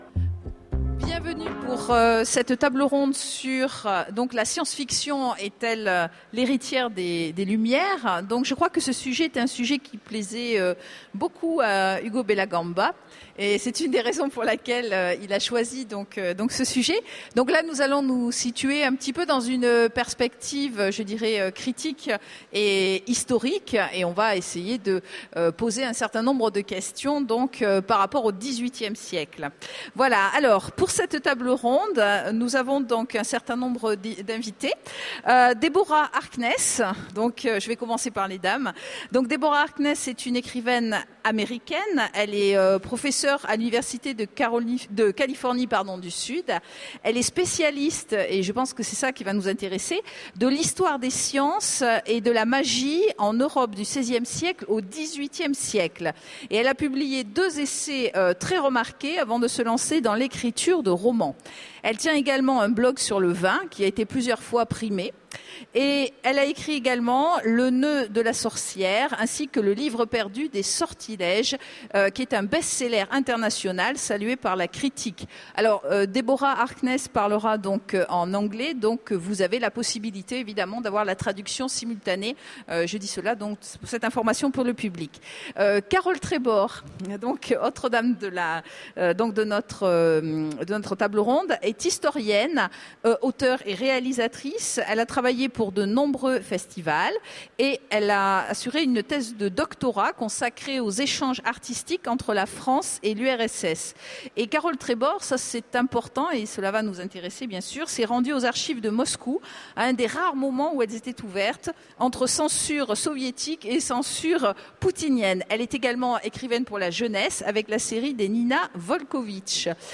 Mots-clés Philosophie Conférence Partager cet article